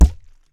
water bottle snare 15 (bonk)
drums percussion snares studio water-bottle sound effect free sound royalty free Nature